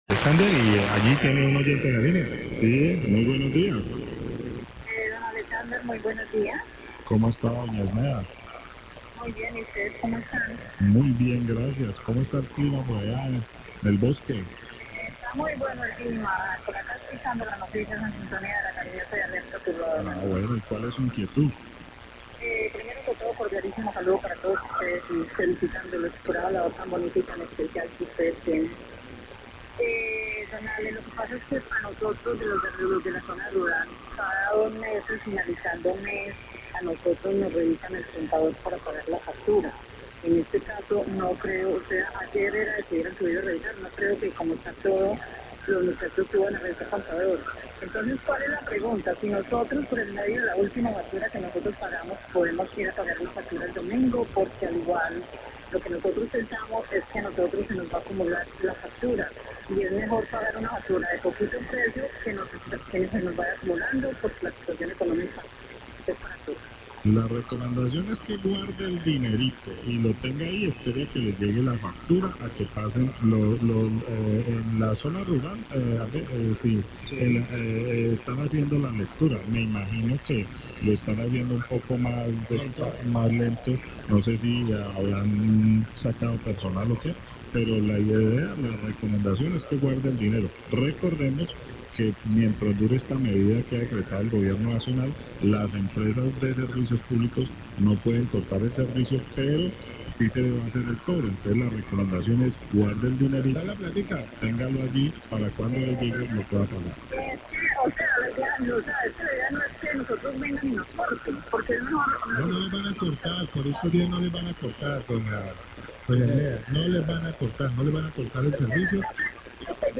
Radio
Oyente de la zona rural pregunta por el recibo para pagar el recibo de la energía teniendo en cuenta que aún no han realizado la lectura de los medidores, la oyente quiere saber si puede pagar con el mismo recibo del mes anterior, no quiere que se le acumule, los periodistas de La Cariñosa le recomiendan esperar, teniendo en cuenta que mientras dure la pandemia no se realizarás cortes en el servicio por falta de pago.